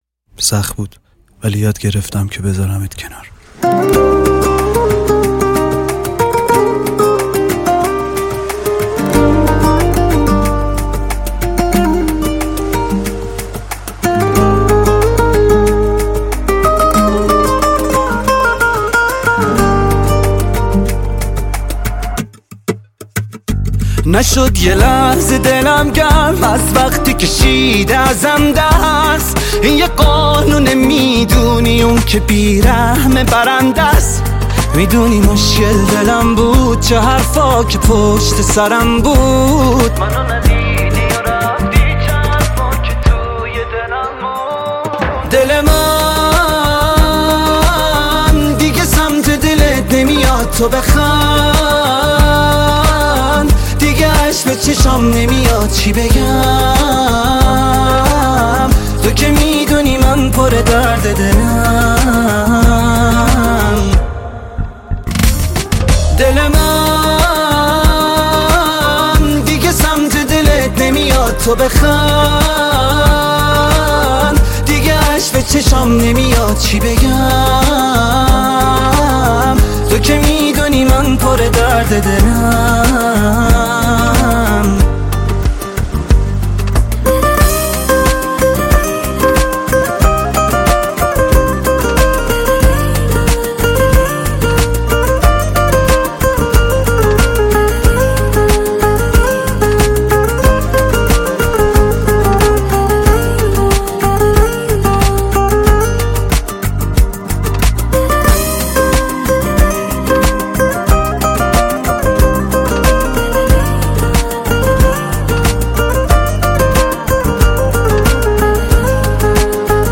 آهنگ ایرانی پست ویژه